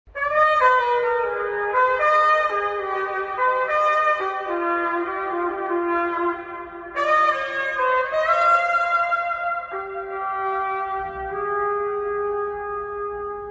Trumpet.mp3